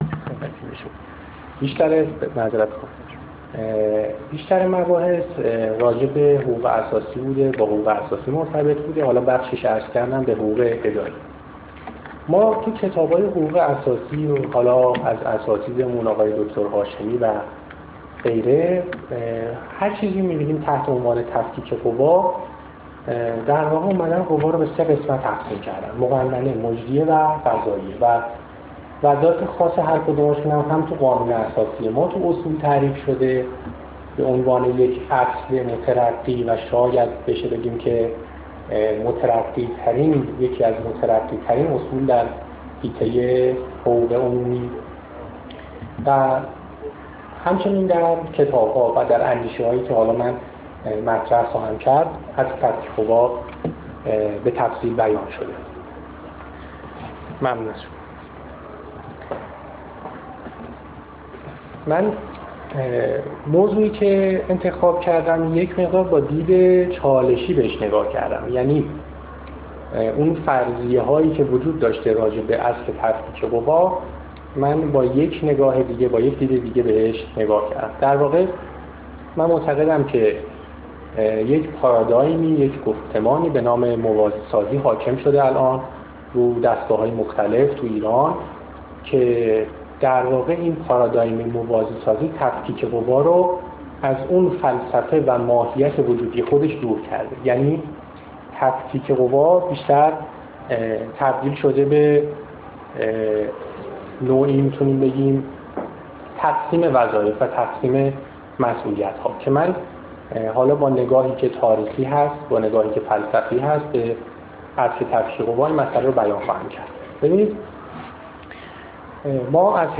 سخنرانی: تفکیک قوا